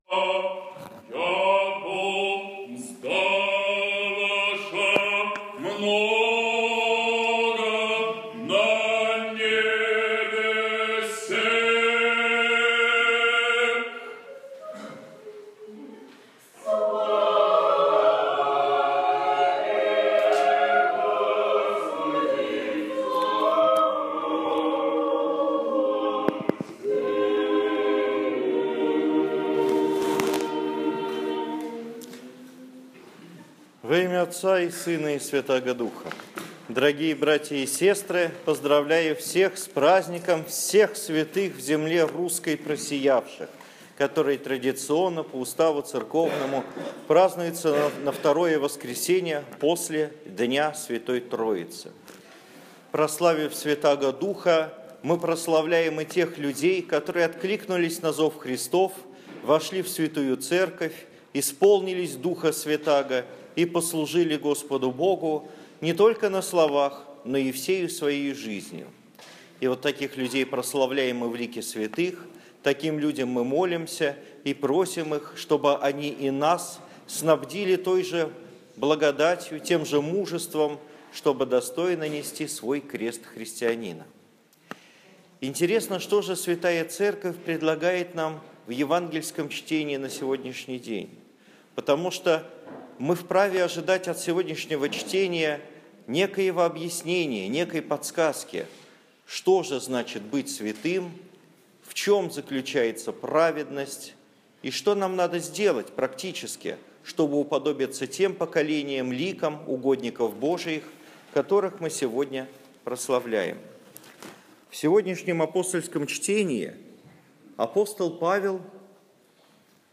Неделя русских святых. Проповедь на литургии в храме свв. апп. Петра и Павла